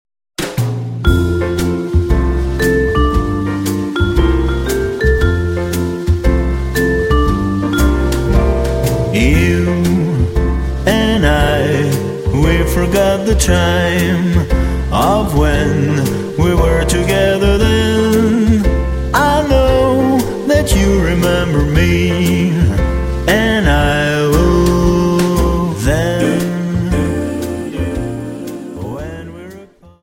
Dance: Slowfox 29